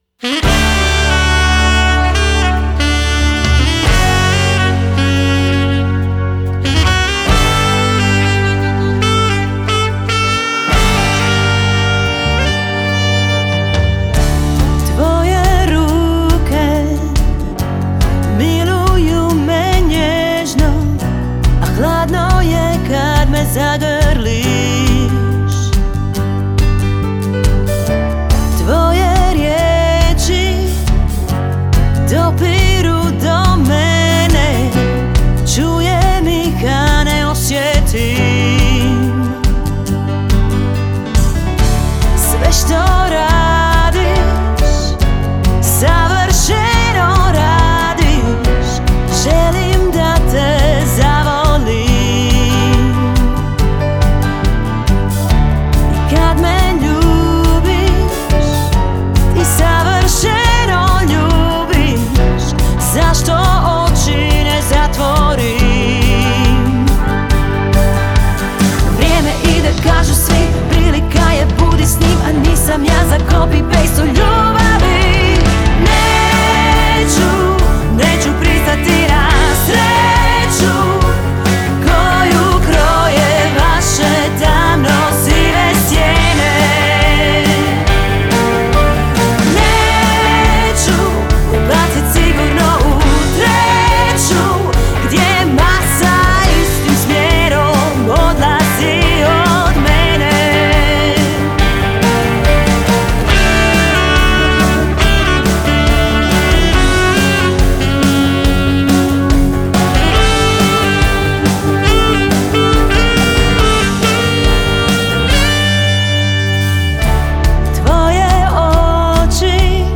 Žanr Pop